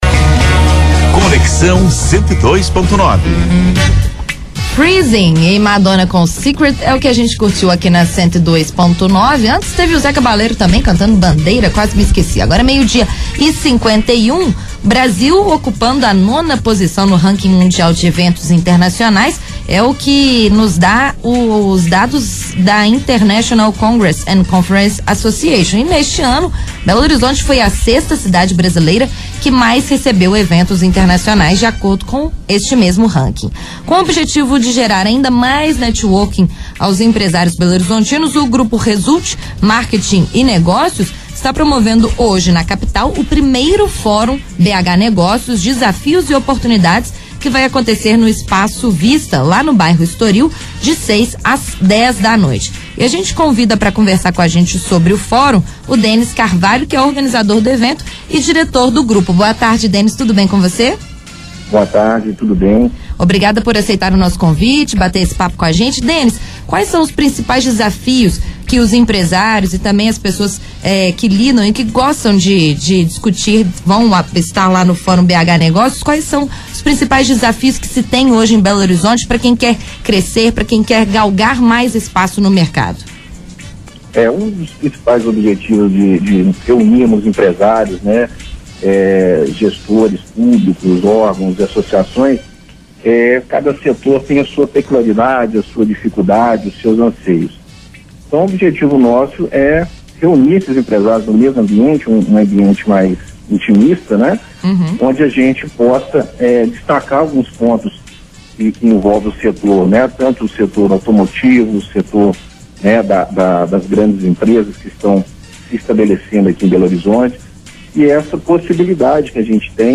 Entrevista
Fórum BH Negócios